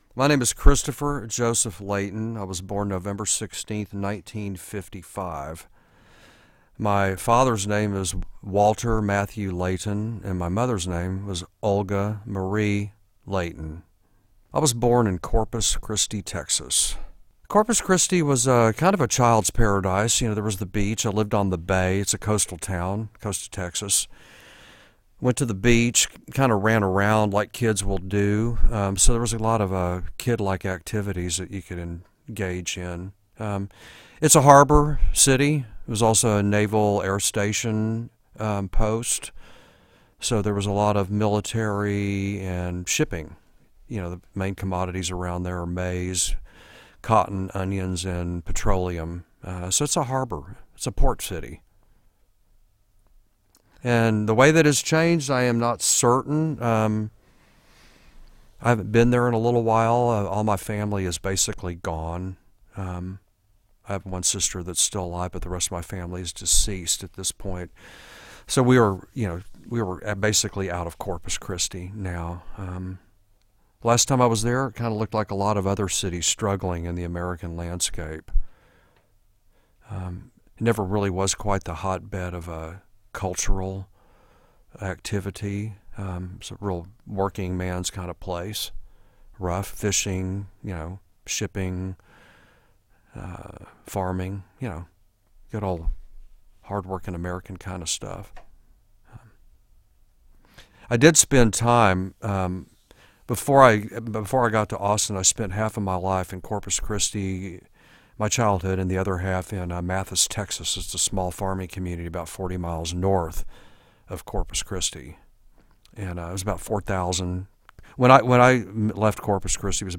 Interview with Chris Layton